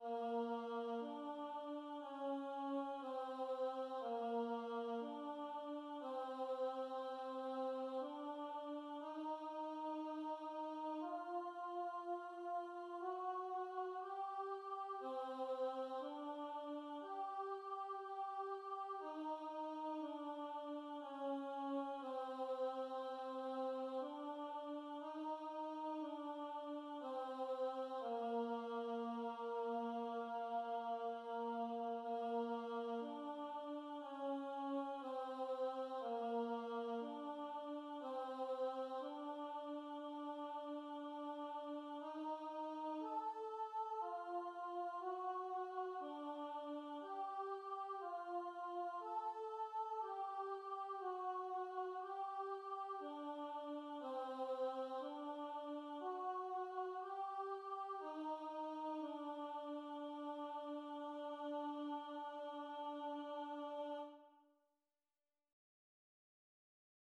Contralti